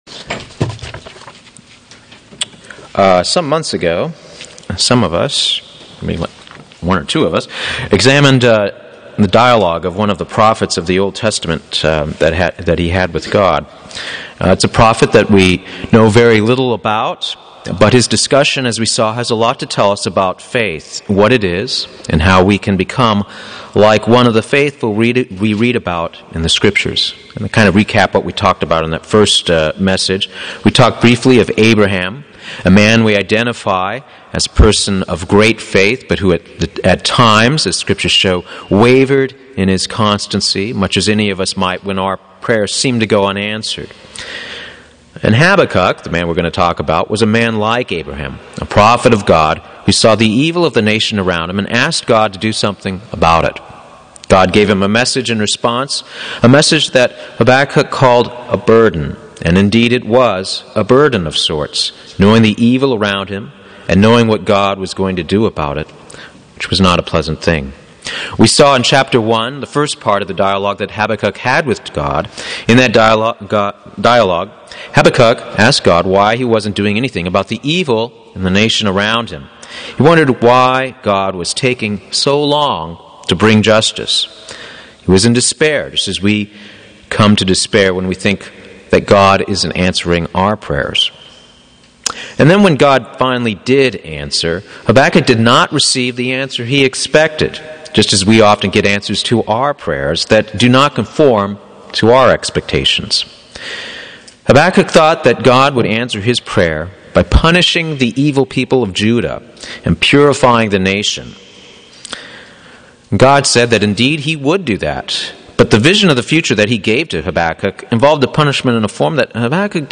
Given in Buford, GA
UCG Sermon Studying the bible?